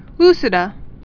(lsĭ-də)